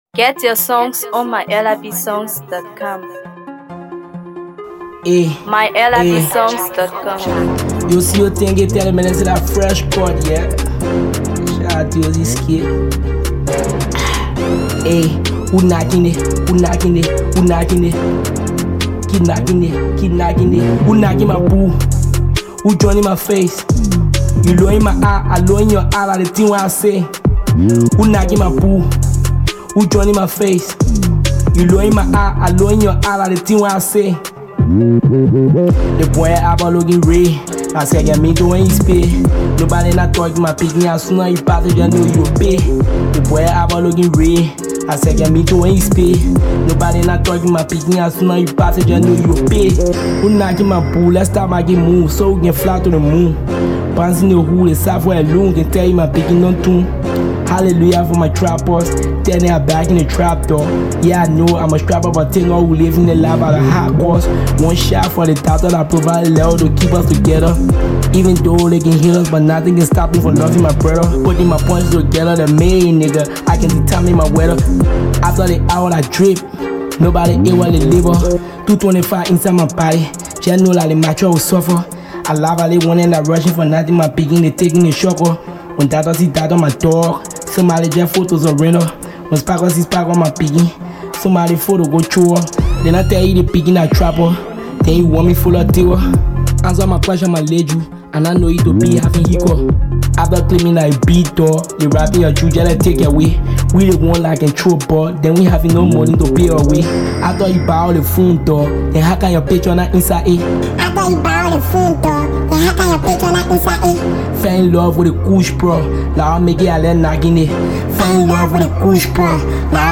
Afro PopMusic
Amazing Trap Banger